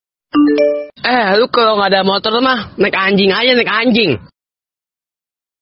Nada notifikasi Eh, Lu kalau enggak ada motor mah, Naik anjing saja naik anjing
Kategori: Nada dering